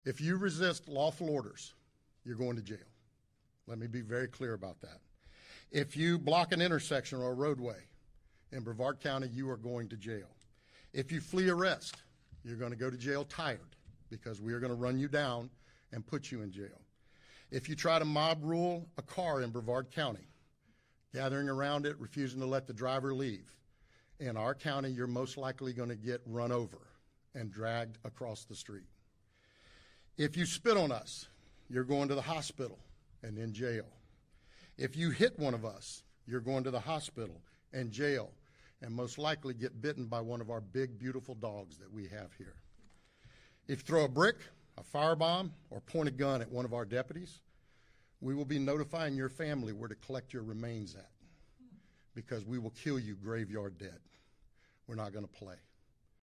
A Florida sheriff warned protesters sound effects free download